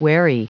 Prononciation du mot wherry en anglais (fichier audio)
Prononciation du mot : wherry